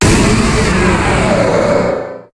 Cri de Méga-Steelix dans Pokémon HOME.
Cri_0208_Méga_HOME.ogg